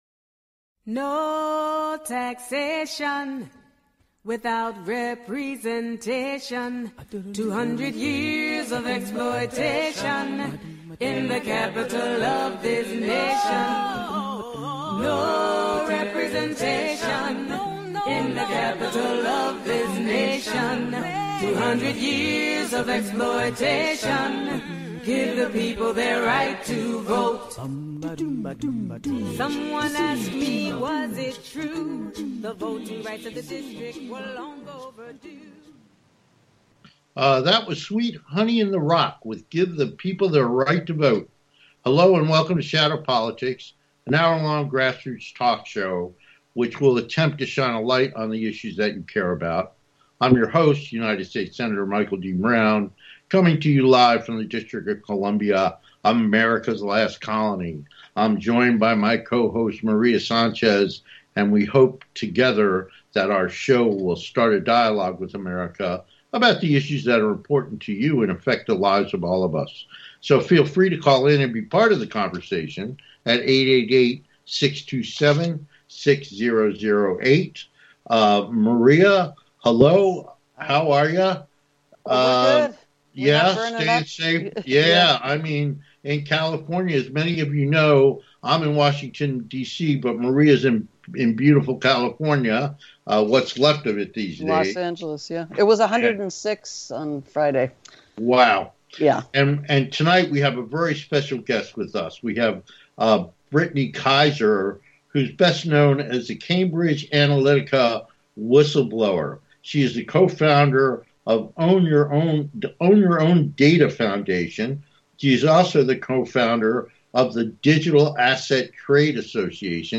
Guest, Brittany Kaiser